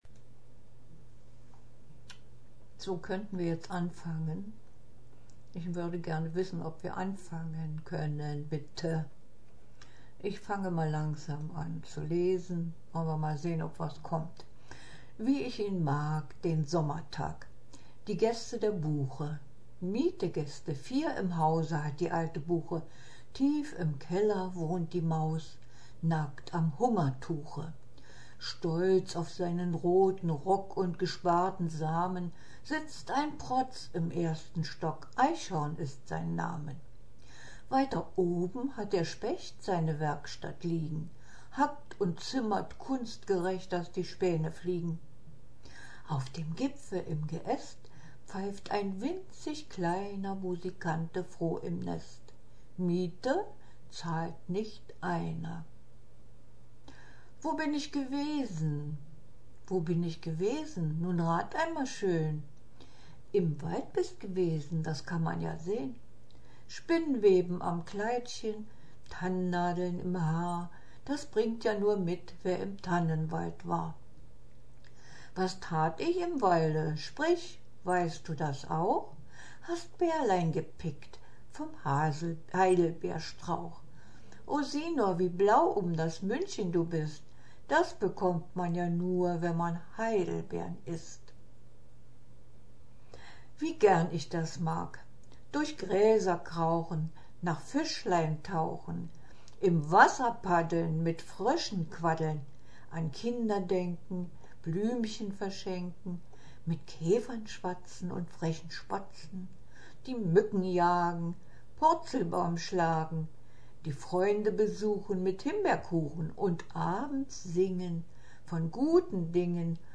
Wie gern ich das mag    (Gesprochener Text)